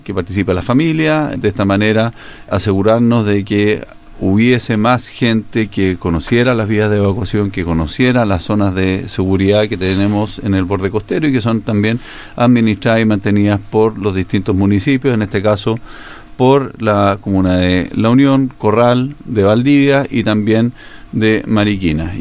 Este año, se decidió hacer un día sábado para que pueda participar toda la familia, tal como lo explicó el director regional de Senapred, Daniel Epprecht.